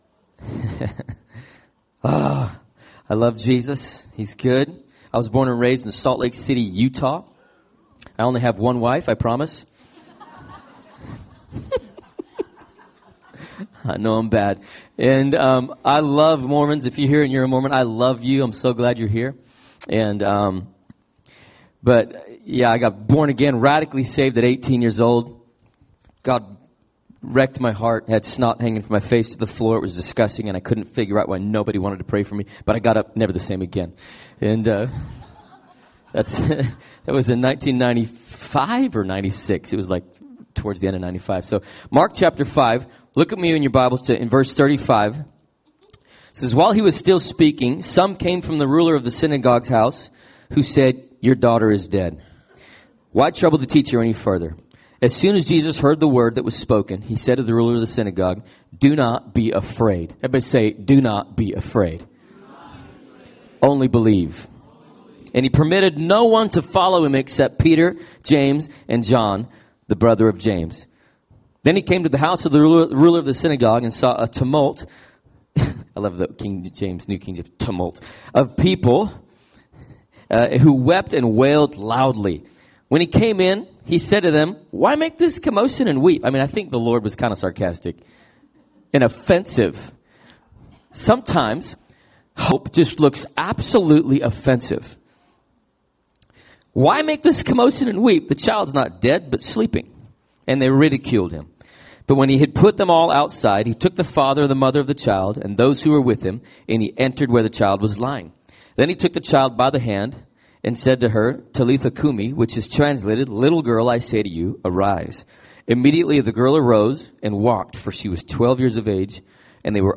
Recorded at New Life Christian Center, Sunday, September 2, 2018 at 9 AM.